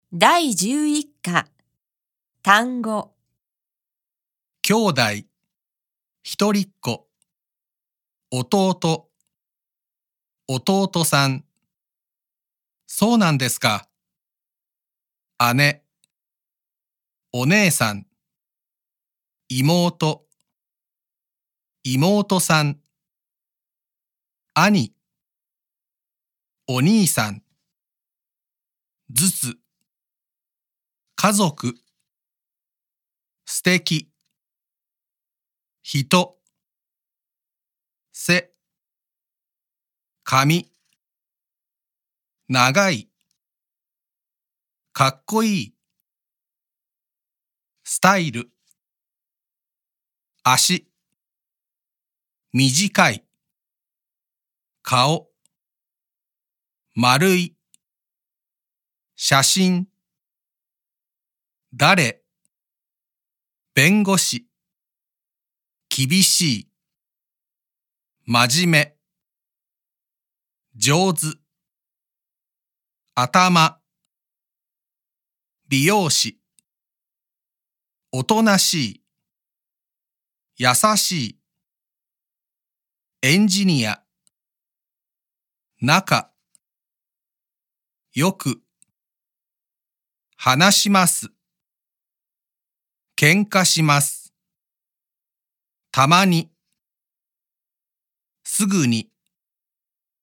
•有聲MP3：由專業日籍錄音老師所錄製的朗讀音源，收錄單字表、長會話、短會話、綜合練習等內容，提供教師配合課程進度在課堂上使用，學生也能在課後練習日語發音和語調。